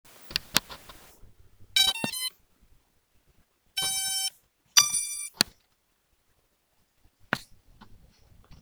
SG700 DRONE'S POWER UP SOUND